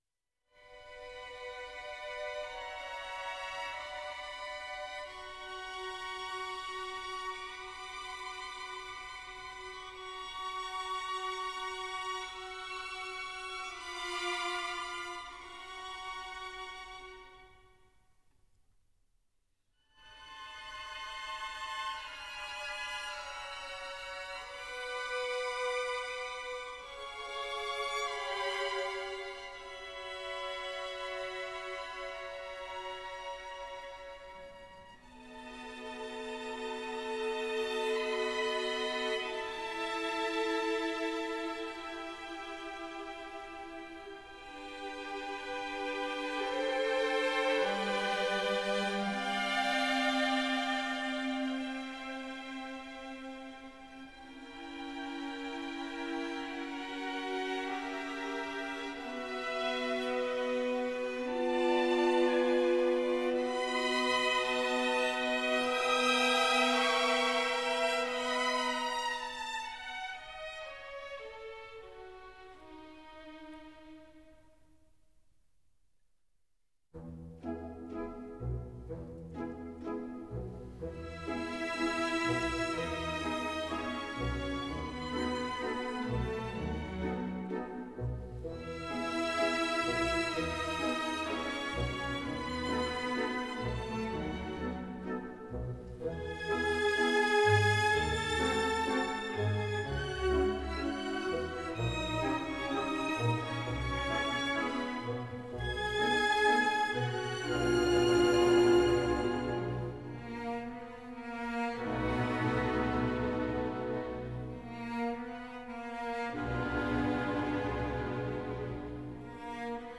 la-traviata-ouverture.mp3